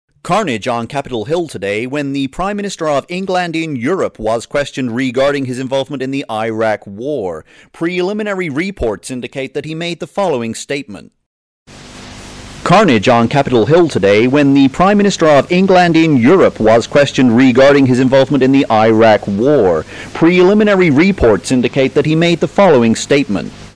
Audio Examples (dry then processed)
It emulates the classic and imperfect texture imparted to audio recorded on video tape.
Take your perfectly good audio and mangle it with an emulation of analog video tape.
DyVision Video Tape Emulator AudioDemo (news report).mp3